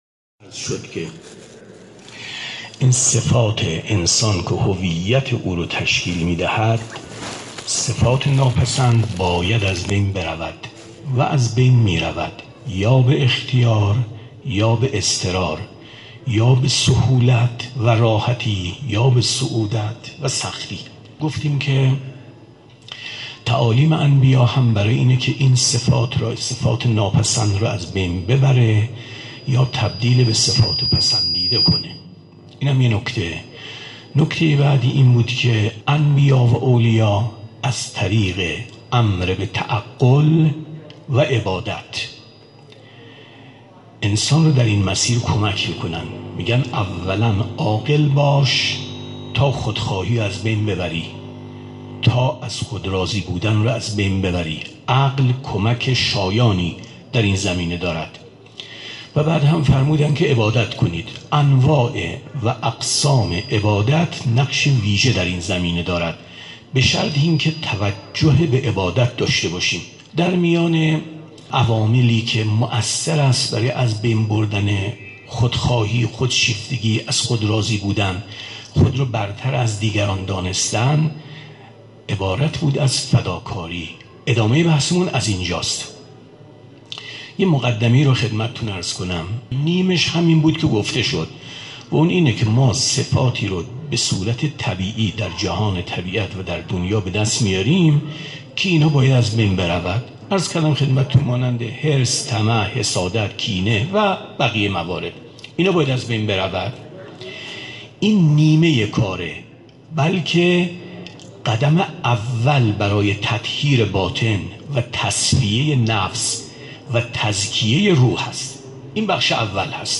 ? گفتارهای دهه اول محرم 1399 ـ (1442 قمری)
مسجد 14 معصوم ـ ابرسج شاهرود ?